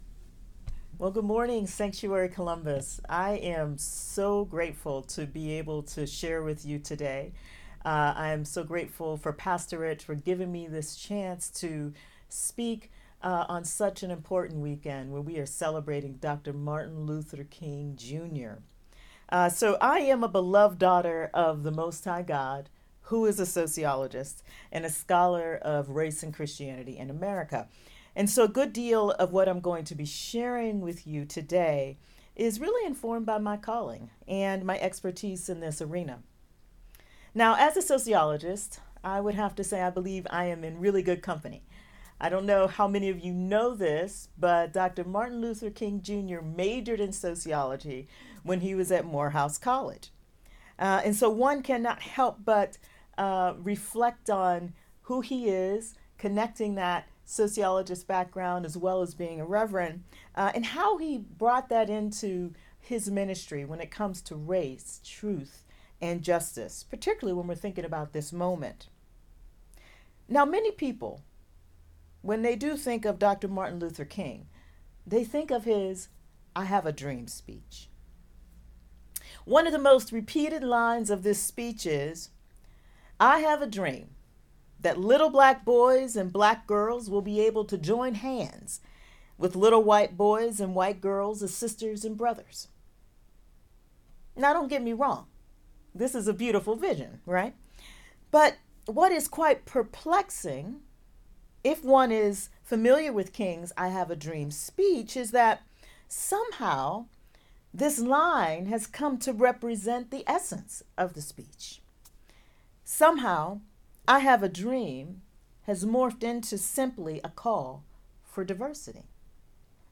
Sermons | Sanctuary Columbus Church